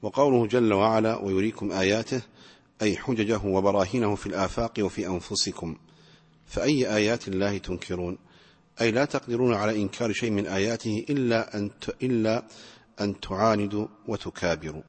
التفسير الصوتي [غافر / 81]